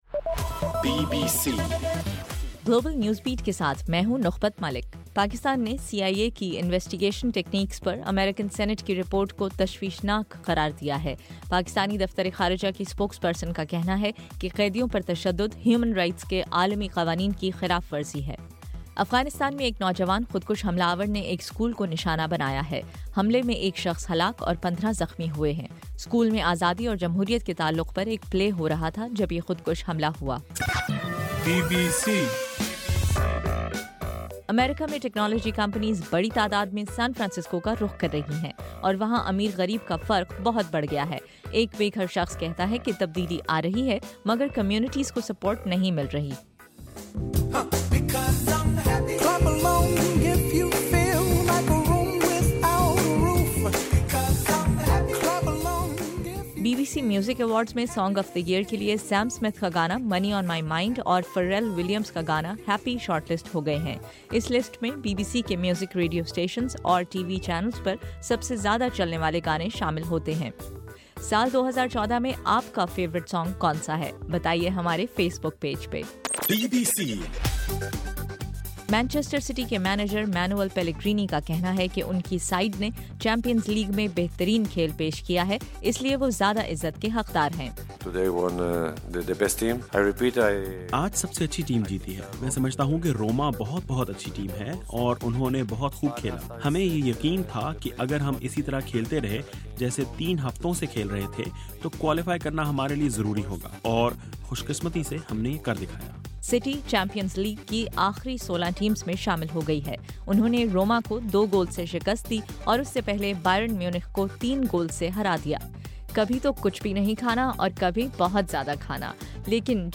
دسمبر 11: رات 12 بجے کا گلوبل نیوز بیٹ بُلیٹن